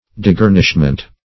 Search Result for " degarnishment" : The Collaborative International Dictionary of English v.0.48: Degarnishment \De*gar"nish*ment\, n. The act of depriving, as of furniture, apparatus, or a garrison.
degarnishment.mp3